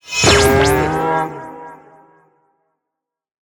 哞菇在转变颜色时随机播放这些音效
Minecraft_mooshroom_convert2.mp3